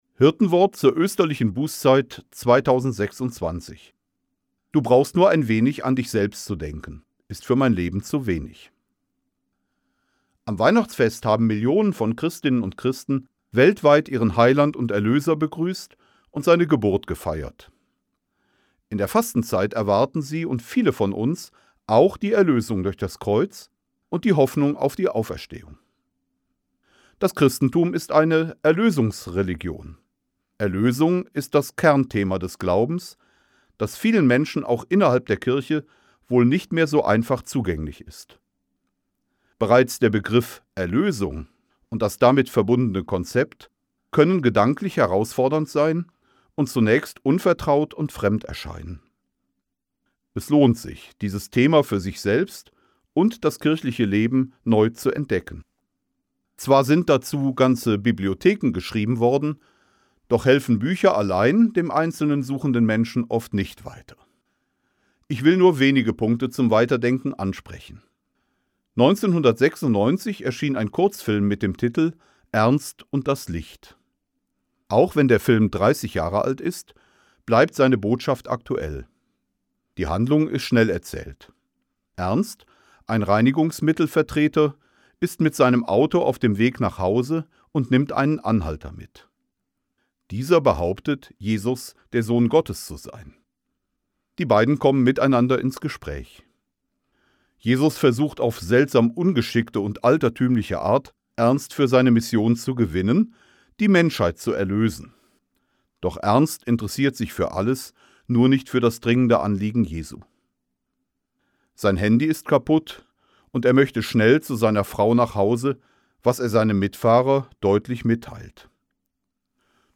Das Hirtenwort 2026 zum Anhören, vorgetragen von Bischof Peter Kohlgraf